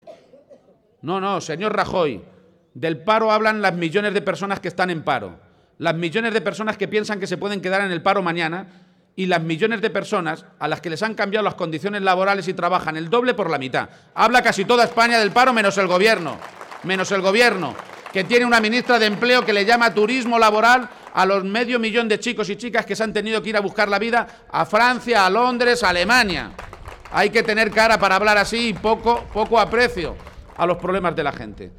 De esta forma rechazaba García-Page la falta de interés por los problemas de la gente que demuestran Rajoy y Cospedal y lo hacía en un acto público celebrado a primera hora de la mañana de hoy en la localidad de Yuncos (Toledo)